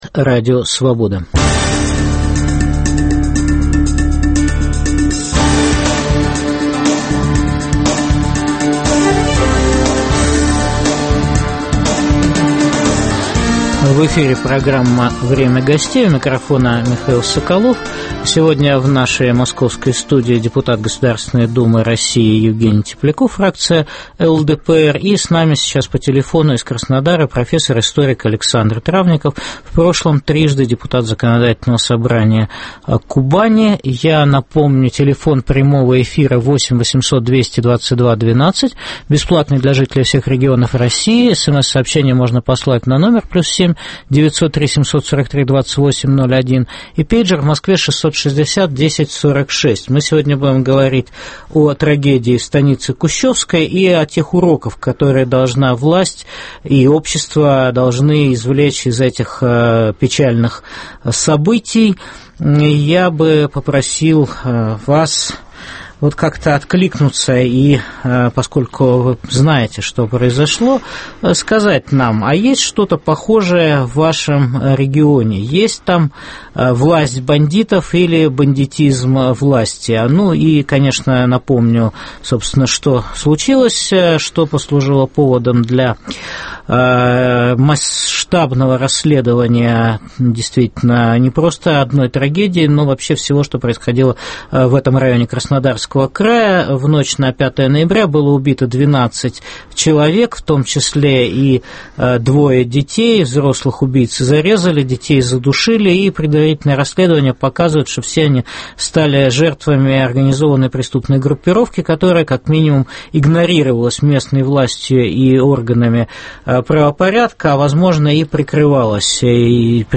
в программе обсуждают депутаты Государственной думы Сергей Обухов (КПРФ), Евгений Тепляков (ЛДПР)